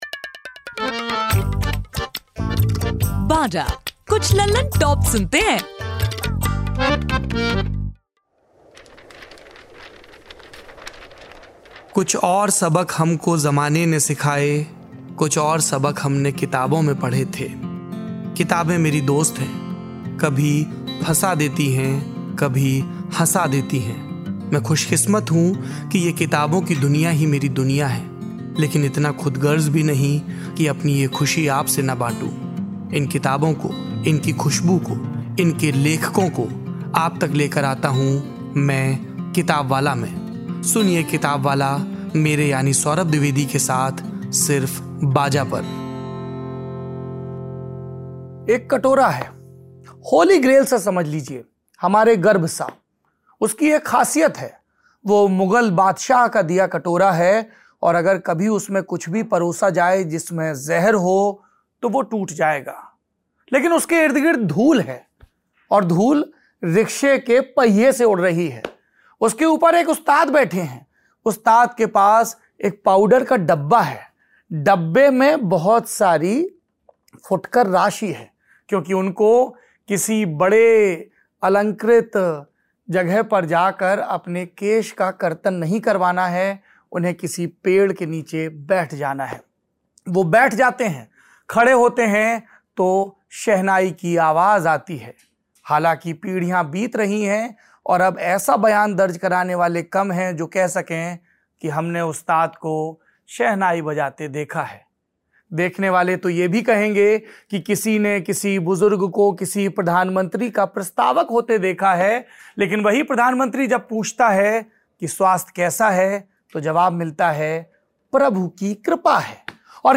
मज़ेदार बातचीत